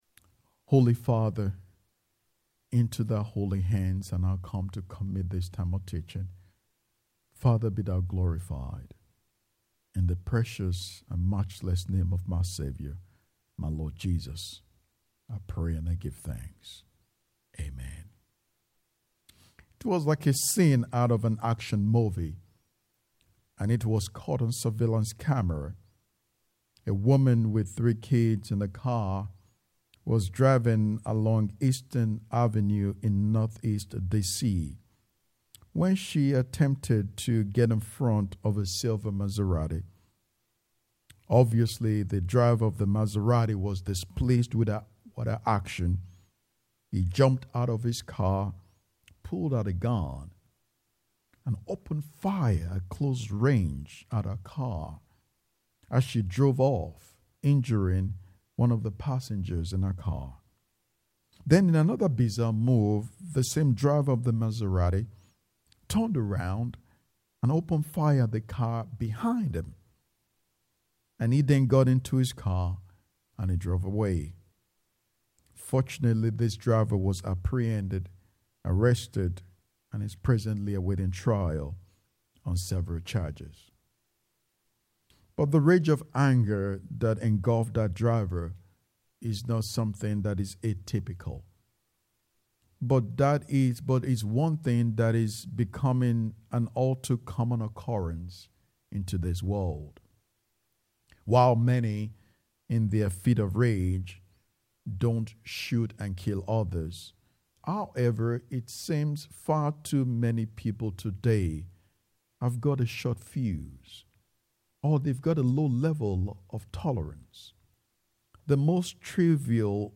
10:30 AM Service
Sat and Sun Sep 6th and 7th 2025 Click to listen to the sermon. https